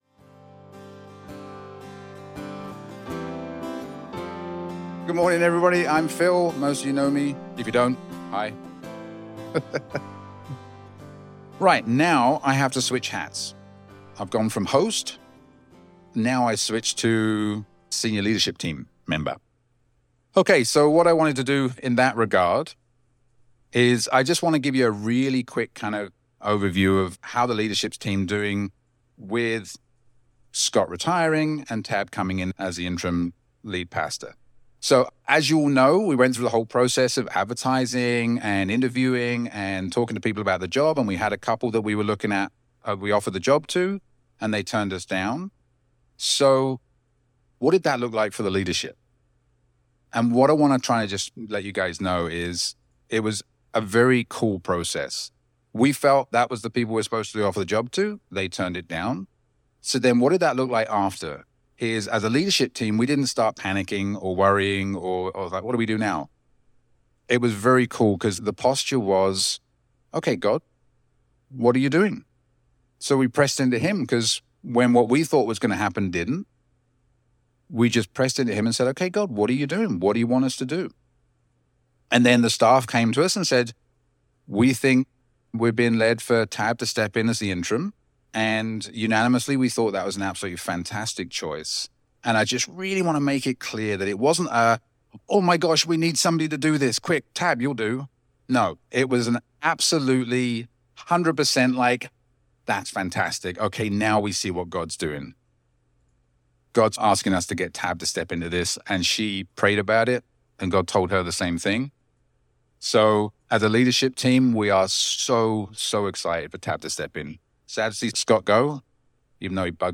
Guest Speaker Service Type: Sunday Morning Always Remember…You Are God’s Beloved My final Sunday as your pastor has now arrived.